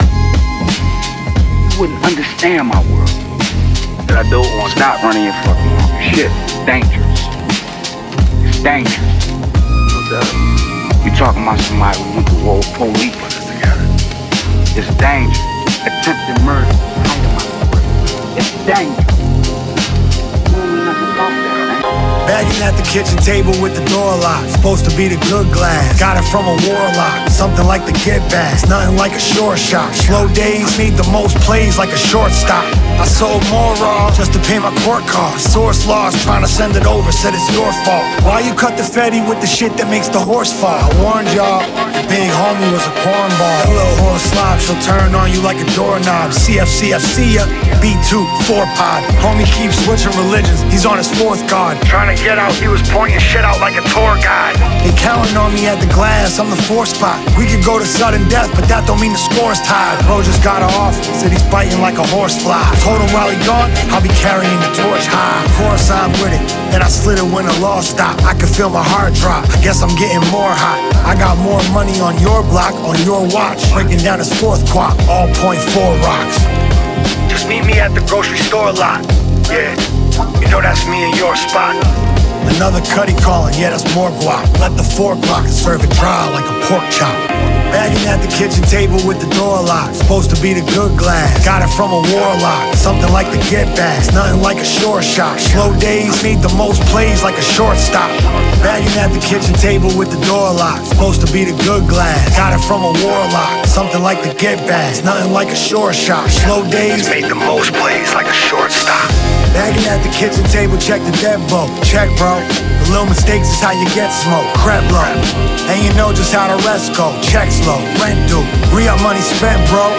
Open Format DJ/Producer